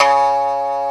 Index of /90_sSampleCDs/AKAI S-Series CD-ROM Sound Library VOL-1/3056SHAMISEN